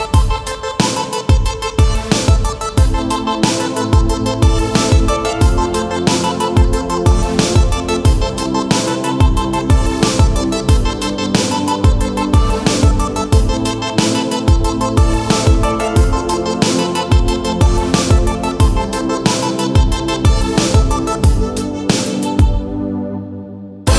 for solo rapper only R'n'B / Hip Hop 3:39 Buy £1.50